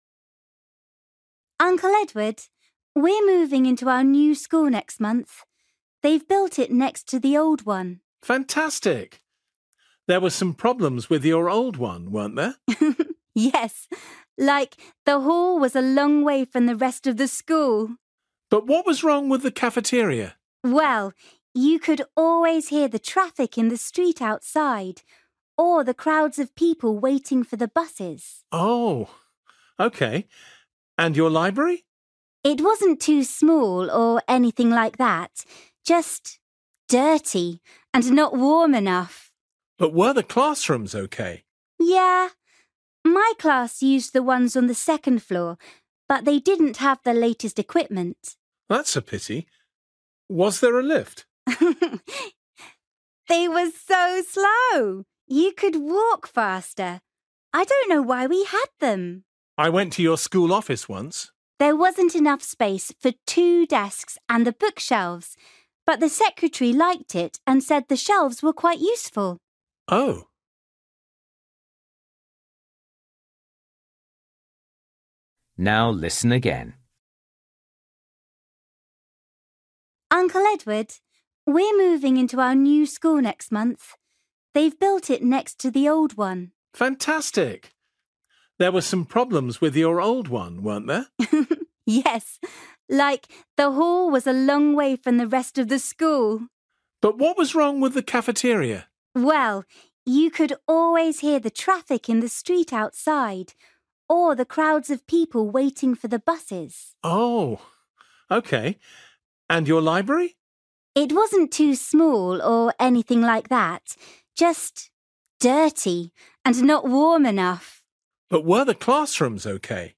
Luyện nghe trình độ A2